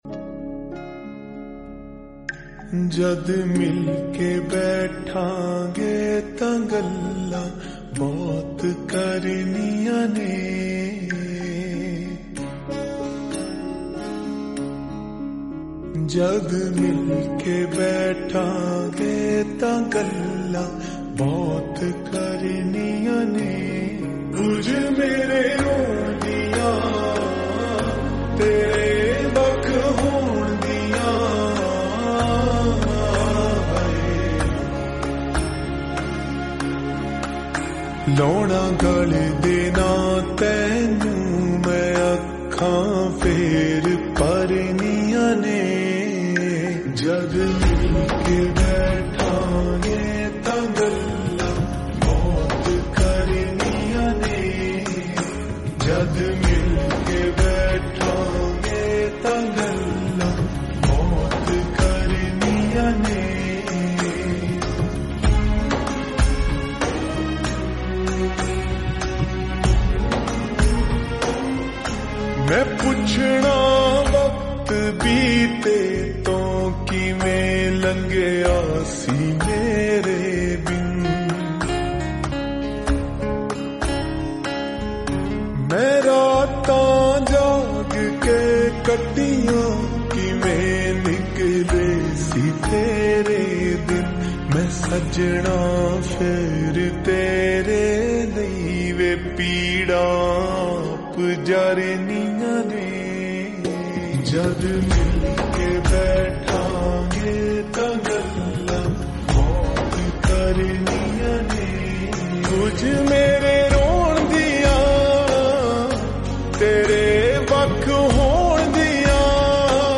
Full song slow and reverb